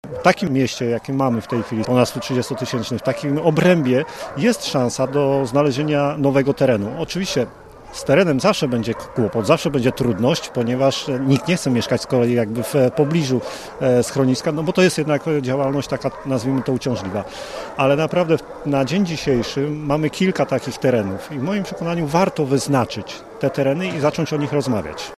Ważną kwestią jest również umiejscowienie nowego schroniska. Mówi Piotr Barczak, miejski radny PiS-u.